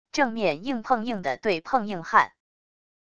正面硬碰硬的对碰硬撼wav音频